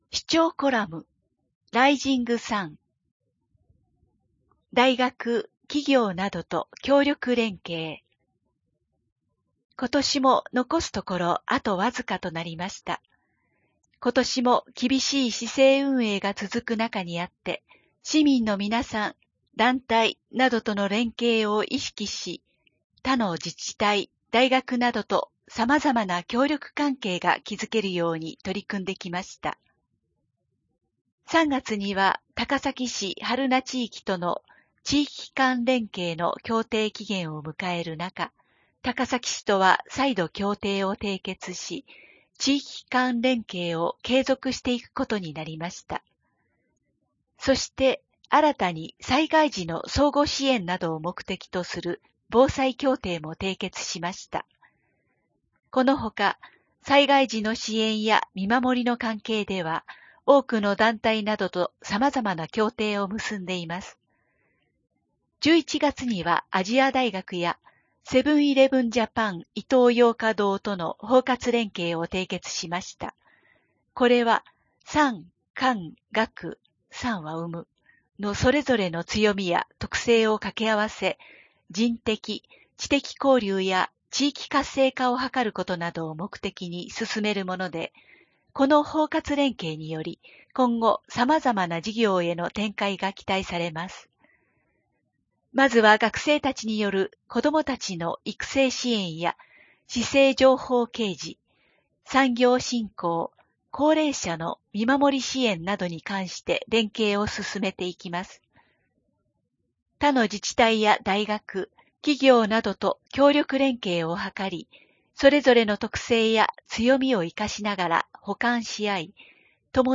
声の広報（平成28年12月15日号）